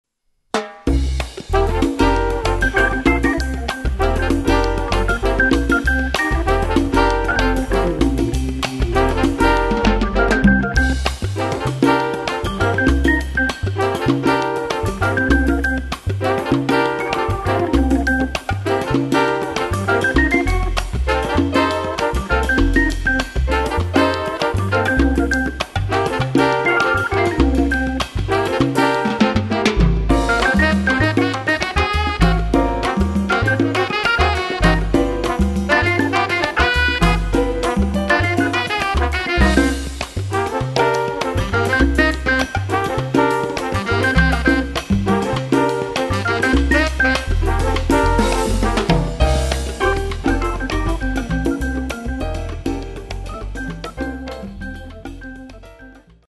Category: little big band
Style: mambo
Solos: open